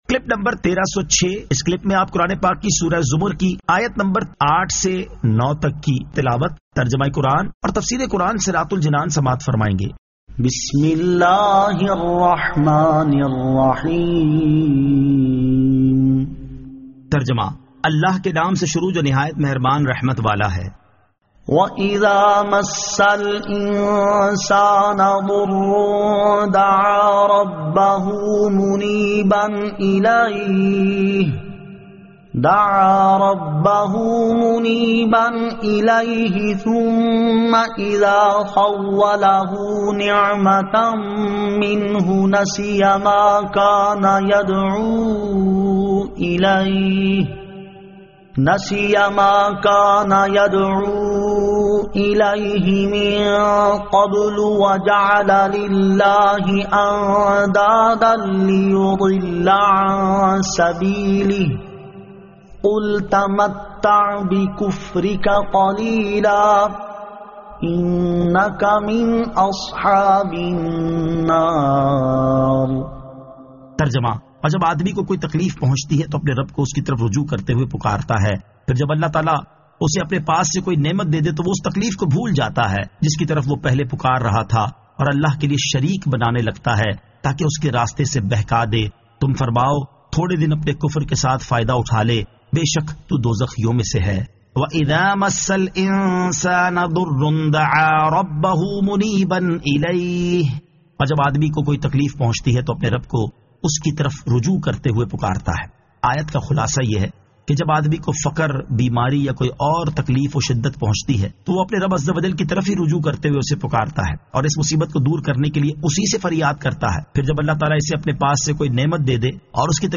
Surah Az-Zamar 08 To 09 Tilawat , Tarjama , Tafseer